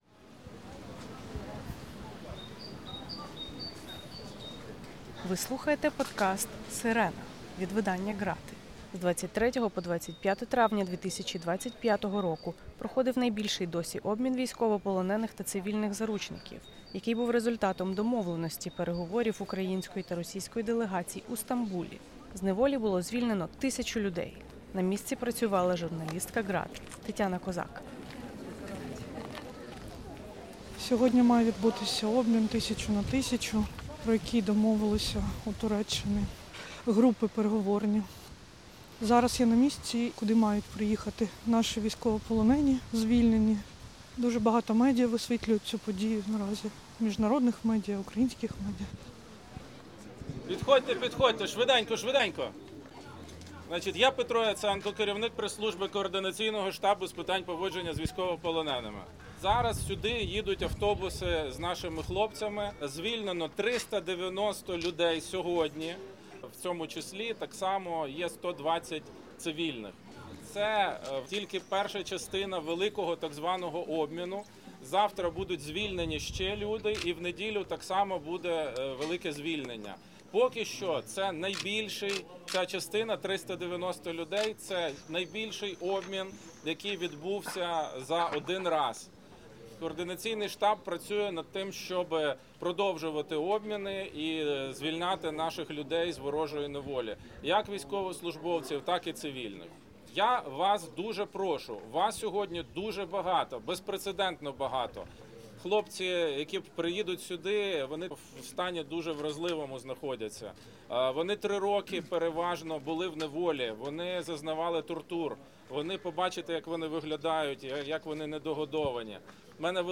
Прибувають автобуси, після довгого очікування все пришвидшується: обійми, вітання, поспішні розпитування й перші дзвінки додому.